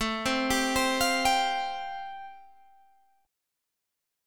Am7#5 chord